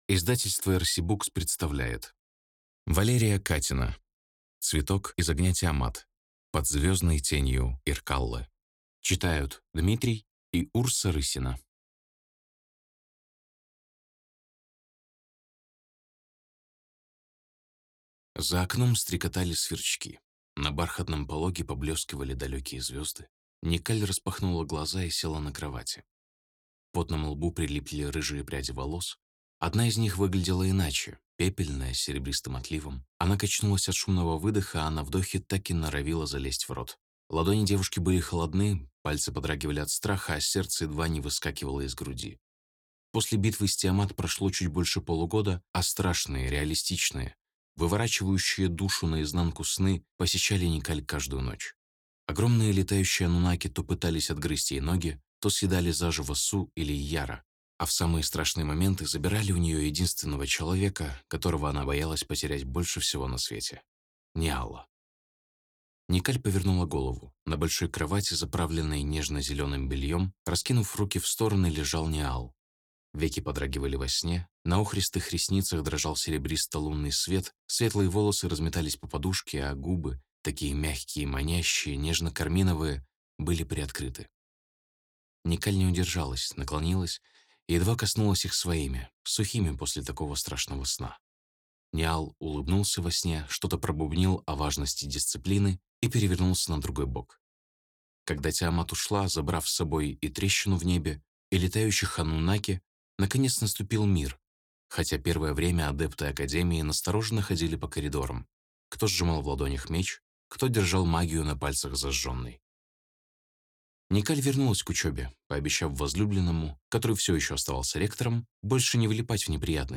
Аудиокниги